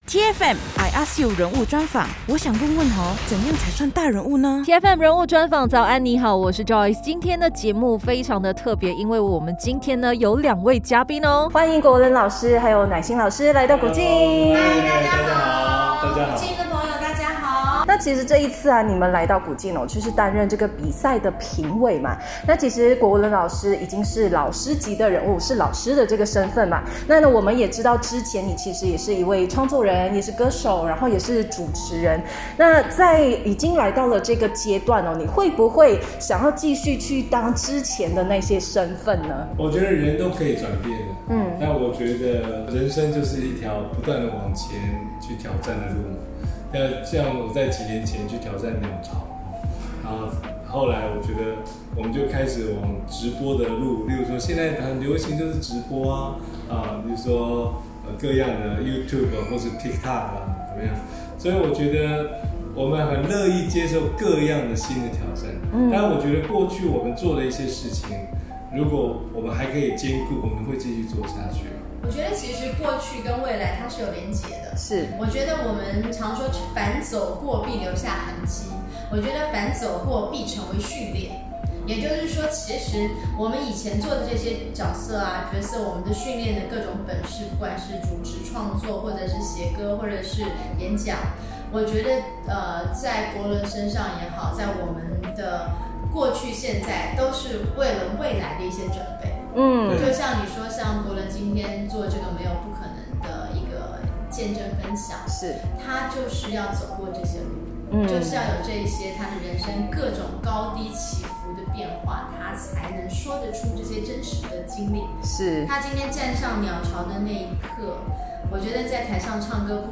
人物专访 黄国伦 寇乃馨